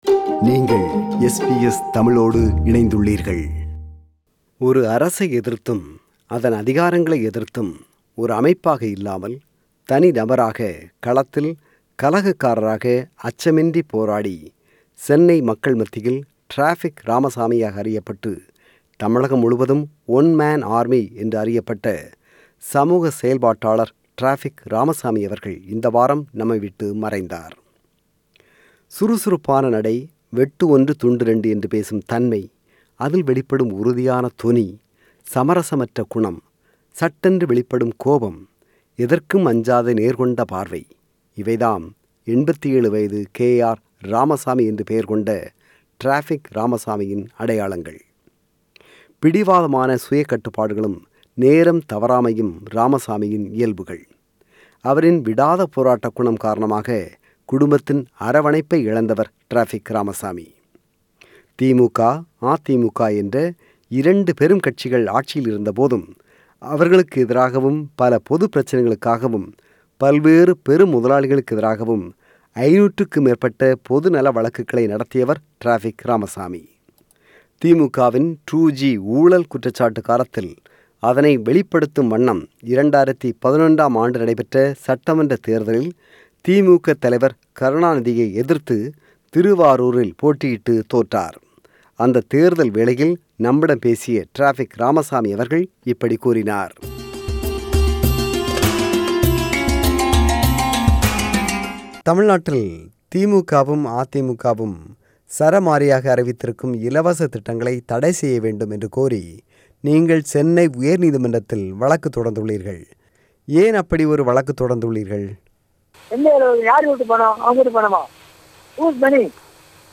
Listen to SBS Tamil at 8pm on Mondays, Wednesdays, Fridays and Sundays on SBS Radio 2.